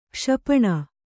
♪ kṣapaṇa